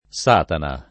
[ S# tana ]